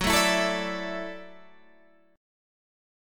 F#m7#5 chord